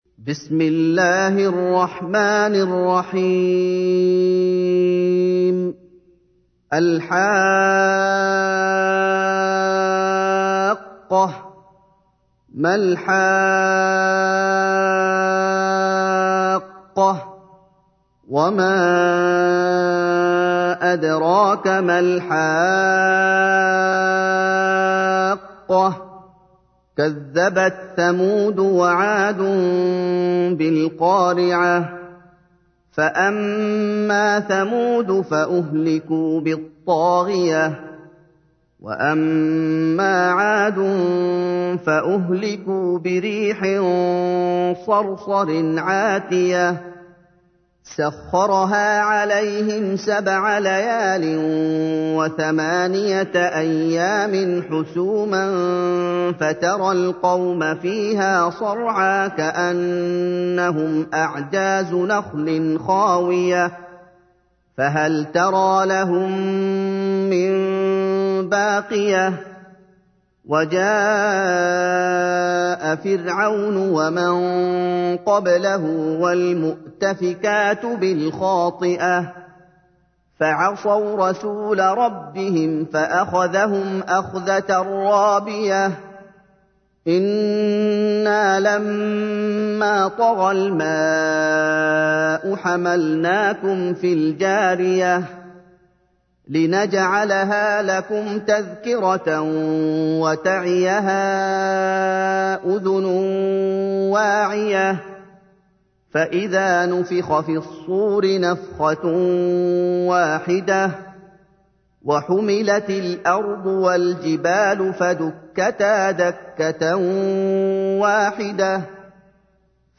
تحميل : 69. سورة الحاقة / القارئ محمد أيوب / القرآن الكريم / موقع يا حسين